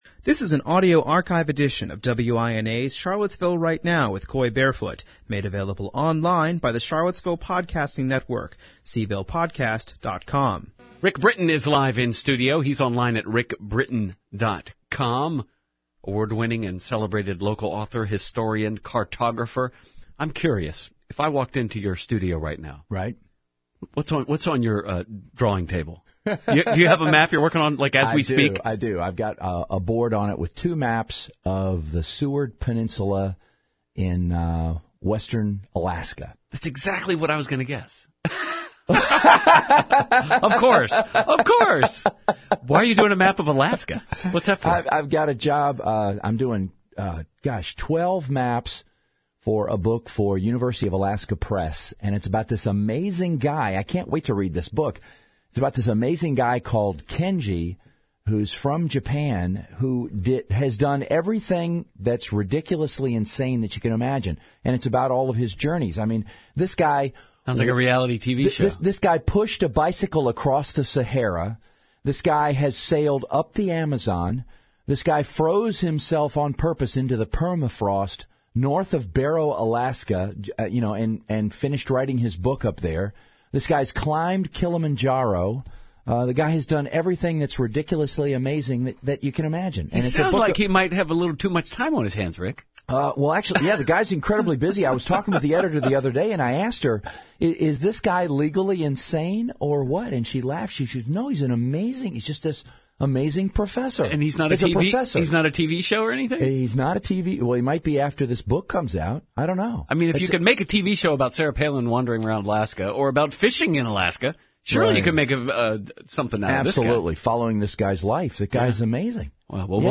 Running from October 7th through November 18th, this six-session lecture series was designed for those interested in Virginia’s fascinating early history. The series focused on some of the big topics that dominated the Old Dominion’s first two centuries, including, Native Virginians, Tobacco, Slavery, the Revolution, George Washington’s Presidency, and Jeffersonian Architecture.